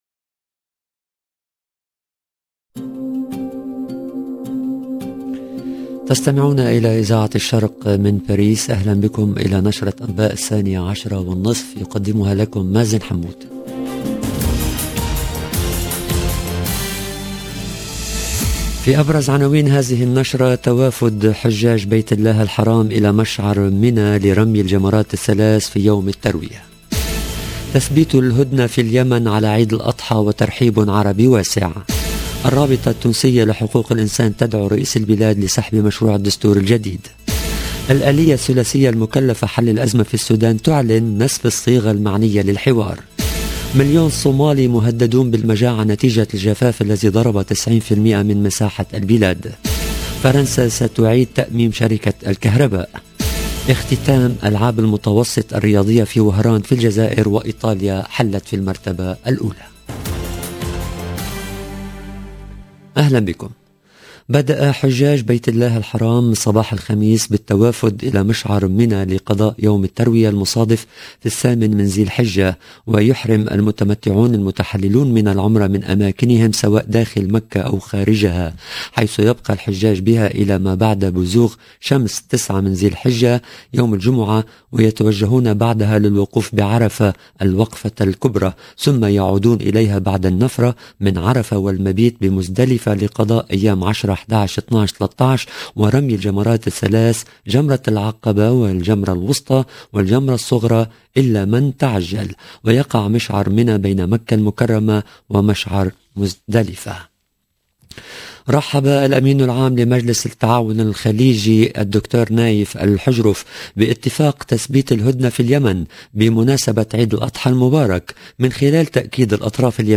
LE JOURNAL DE MIDI 30 EN LANGUE ARABE DU 7/07/22